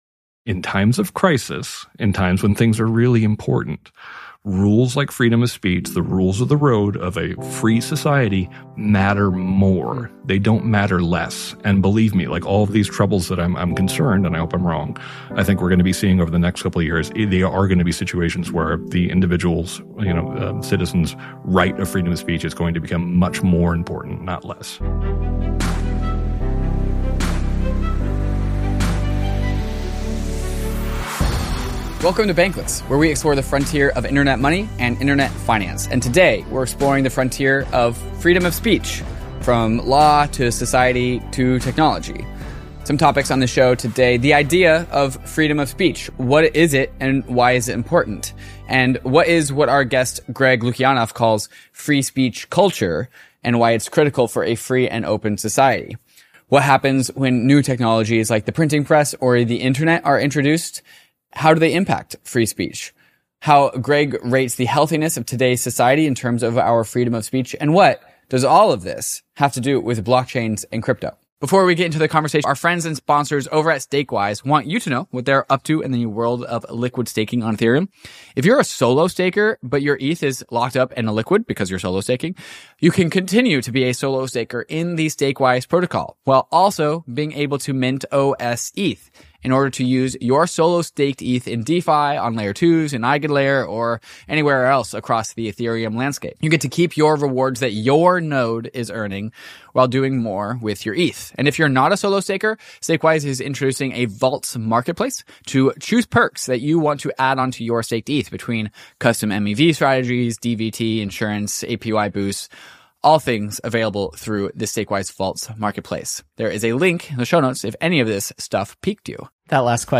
That’s the question that Free Speech Lawyer and Writer Greg Lukianoff helps us answer today.